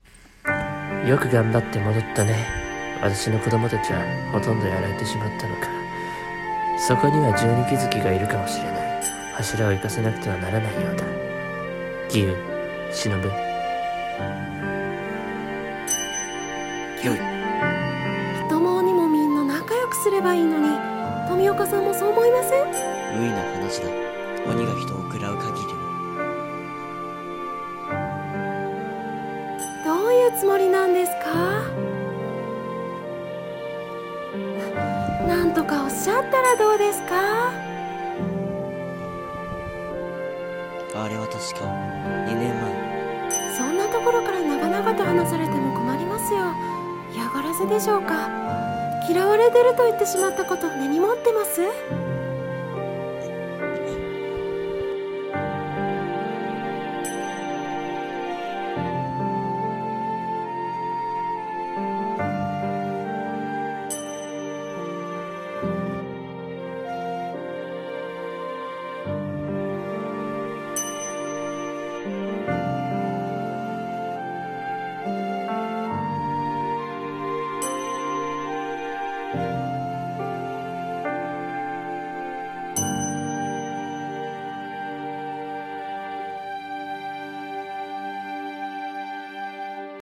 鬼滅の刃声劇（御館様･水柱･蟲柱）